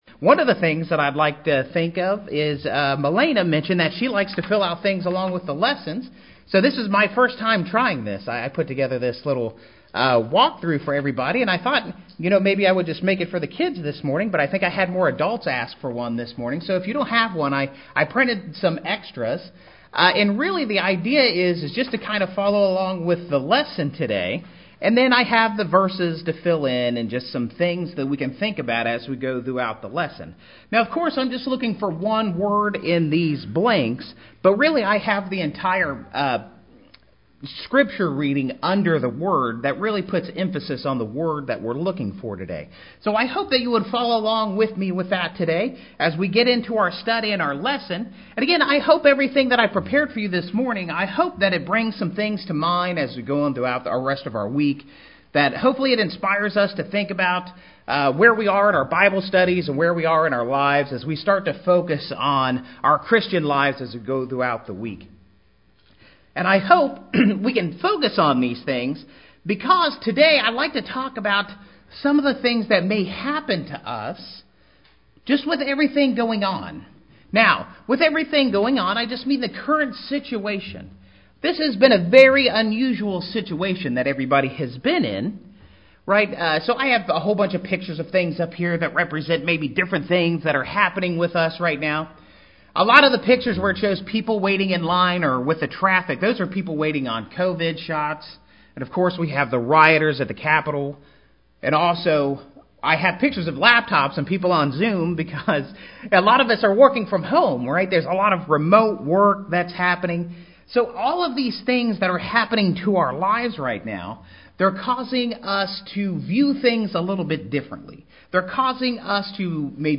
We need to cast our problems on God and spend time in prayer and meditation. Click the play button to hear the lesson and follow along by viewing the slides!